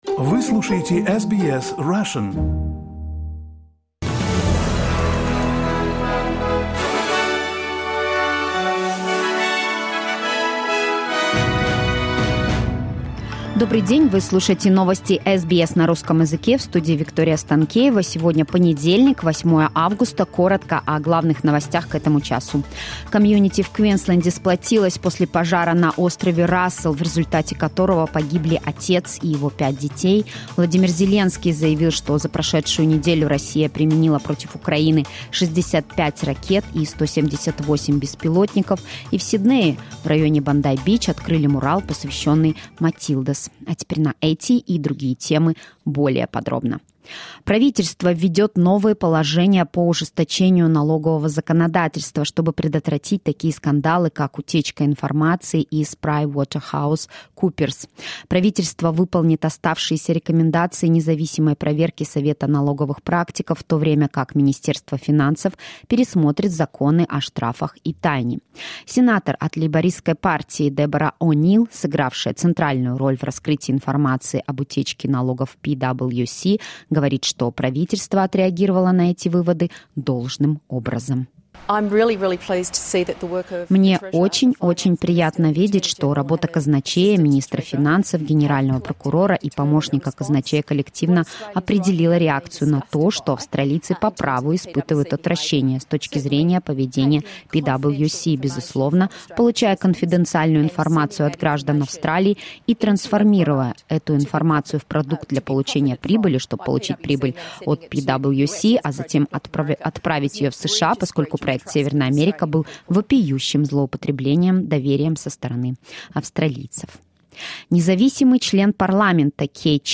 SBS news in Russian — 07.08.2023